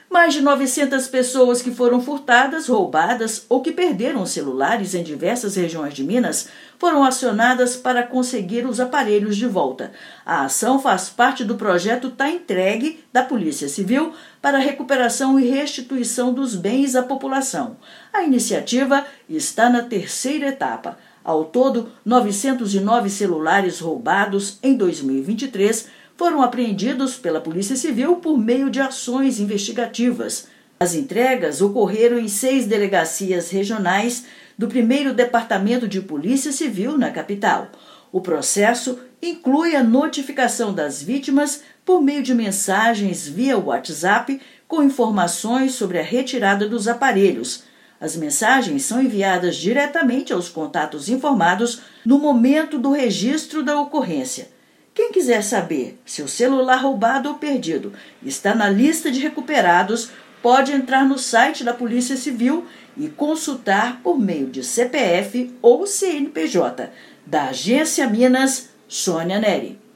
Restituições ocorreram em seis delegacias da Polícia Civil em Belo Horizonte nesta terça-feira (20/5). Ouça matéria de rádio.